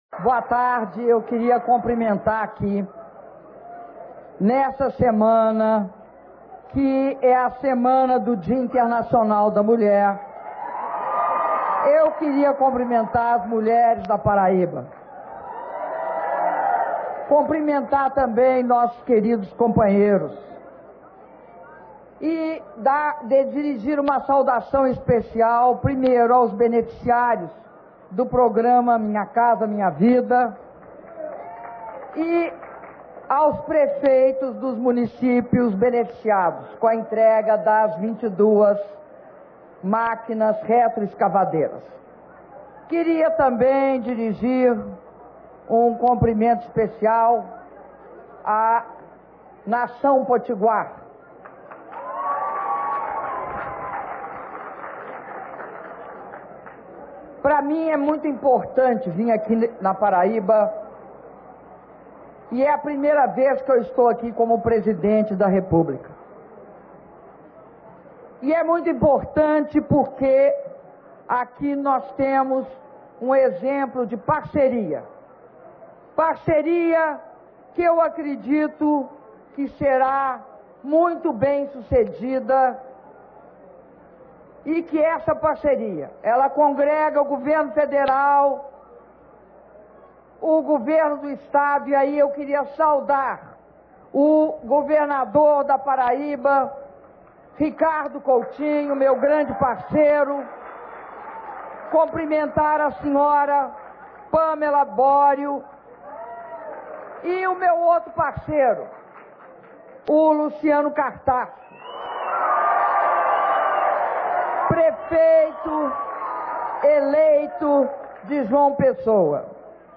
Discurso da Presidenta da República, Dilma Rousseff, na cerimônia de entrega de 576 unidades habitacionais do Residencial Jardim Veneza e de 22 máquinas retroescavadeiras a municípios do estado da Paraíba - João Pessoa/PB